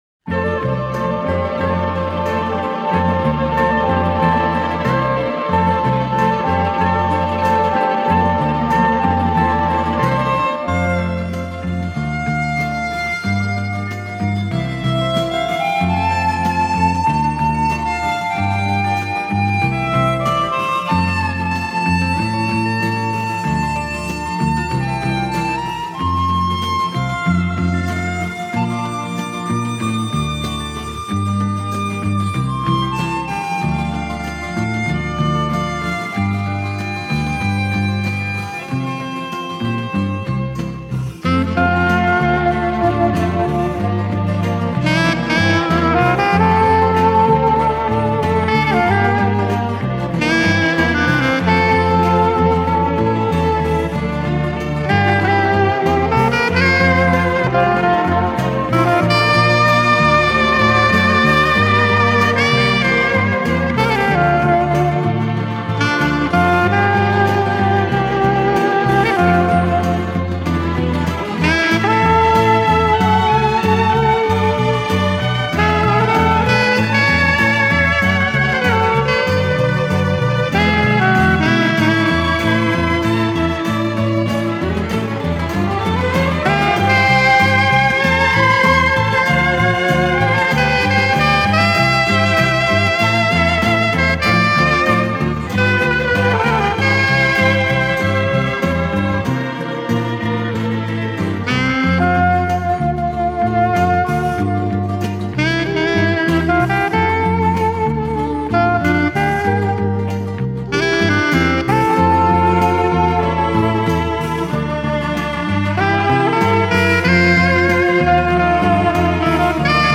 Жанр: Jazz, Pop, Easy Listening